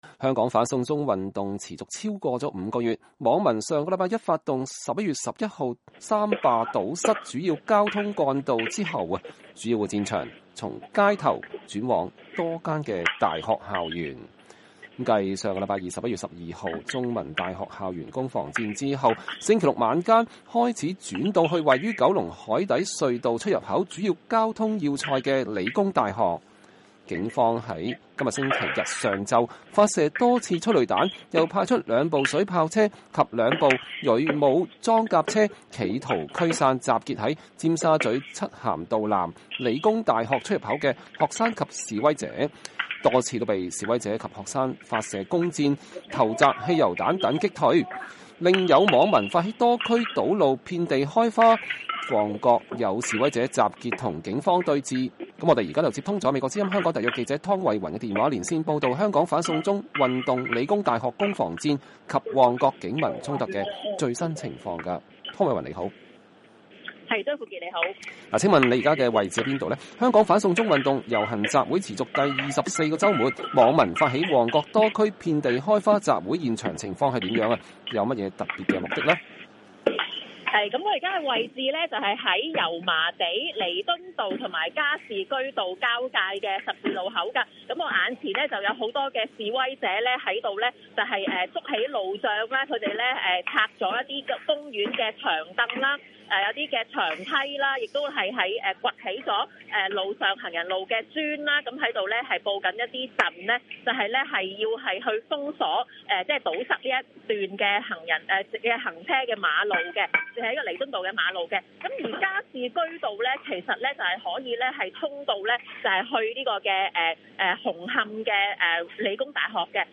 香港理工大學激烈攻防戰 旺角警民衝突現場報導